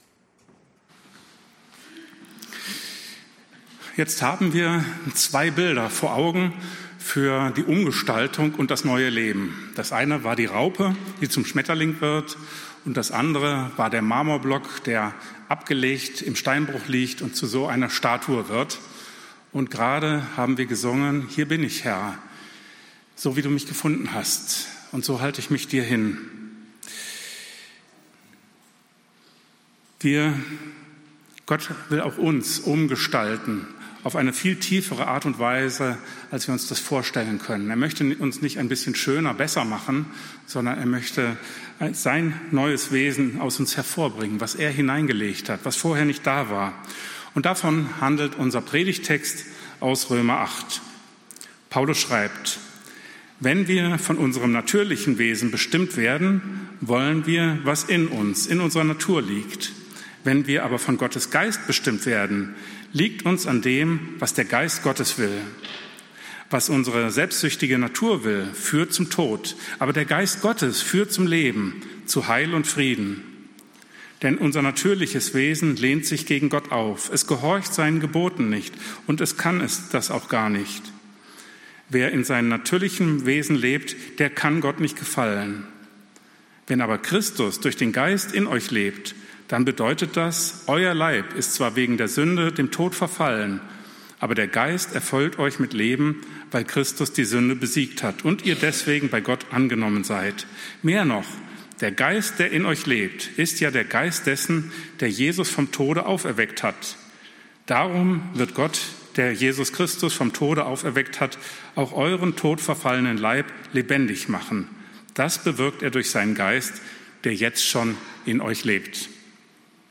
EFG-Haiger Predigt-Podcast Der Heilige Geist - Erneuerung von innen nach außen Play Episode Pause Episode Mute/Unmute Episode Rewind 10 Seconds 1x Fast Forward 30 seconds 00:00 / 22:57 Abonnieren Teilen RSS Feed Teilen Link Embed